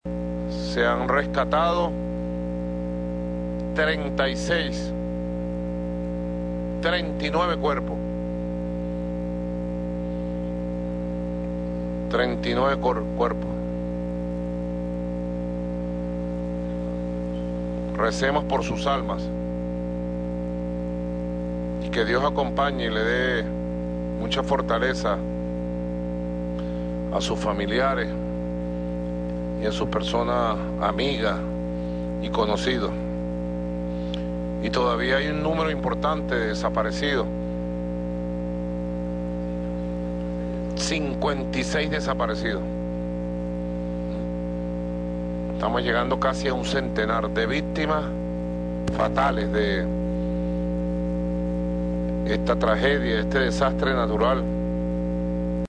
«Se han rescatado 39 cuerpos… Y todavía hay un número importante de desaparecidos, 56 desaparecidos. Estamos llegando a casi un centenar de víctimas fatales de esta tragedia», dijo en transmisión a través del canal del Estado.